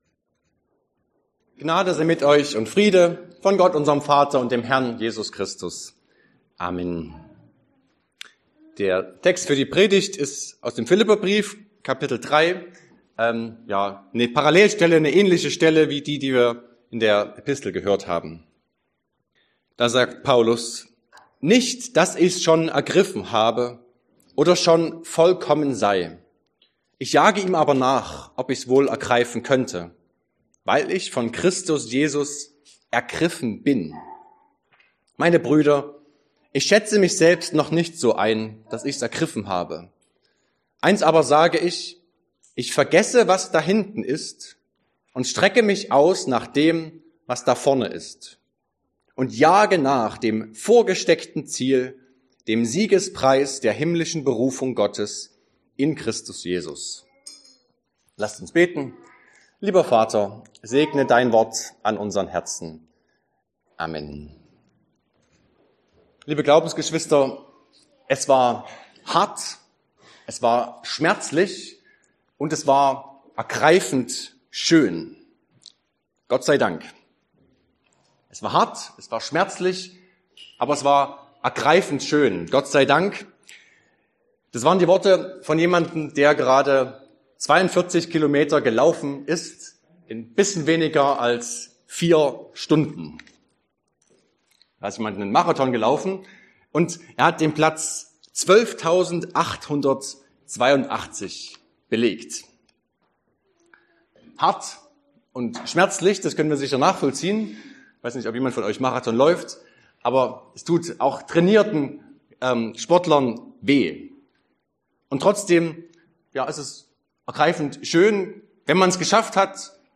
Septuagesimä Passage: Philipper 3, 12-14 Verkündigungsart: Predigt « Letzter Sonntag nach Epiphanias 2025 Estomihi